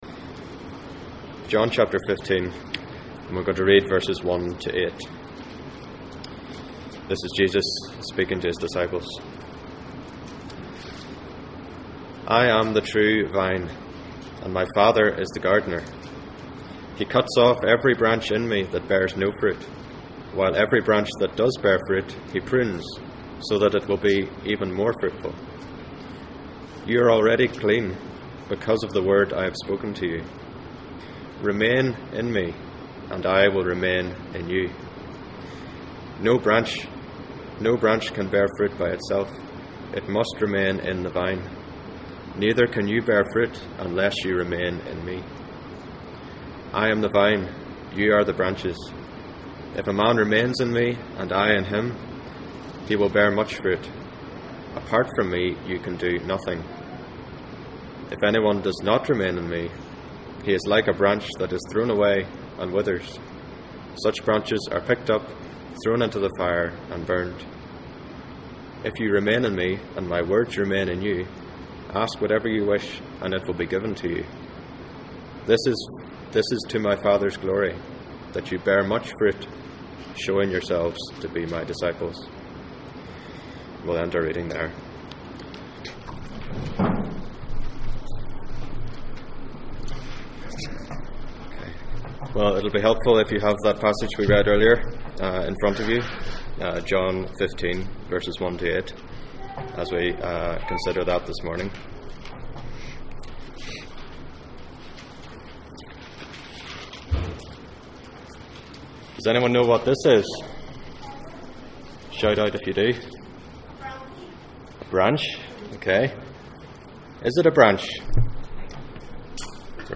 2 Peter 1:3-11 Service Type: Sunday Morning %todo_render% « Jesus punctures the conspiracy